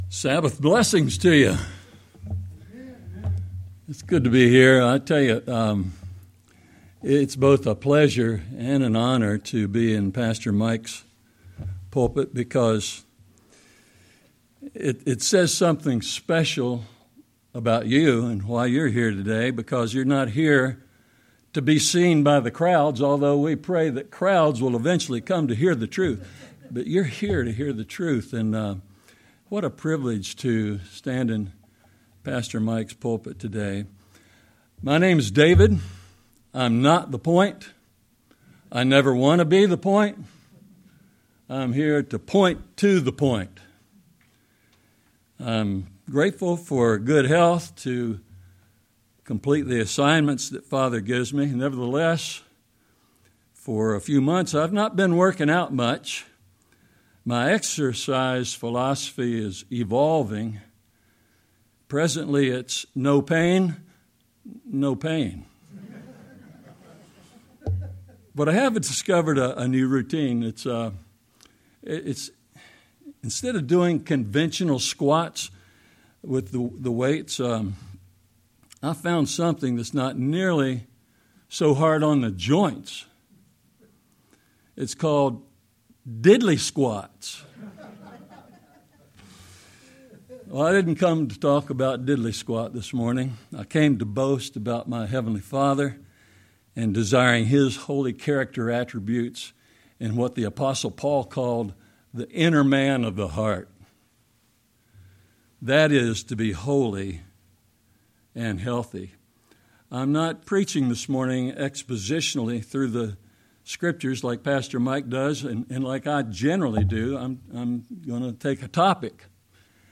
Sermon-3-16-25.mp3